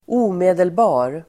Uttal: [²'o:me:delba:r]